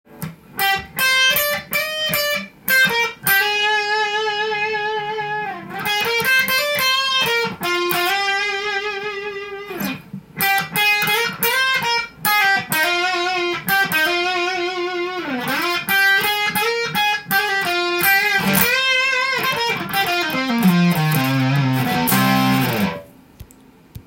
歪ませて弾いてみると音がもの凄く伸びます。
非常に弾きやすく、音が太いです。